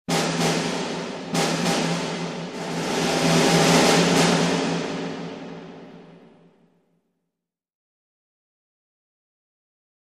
Music Logo; March Snare Introduction.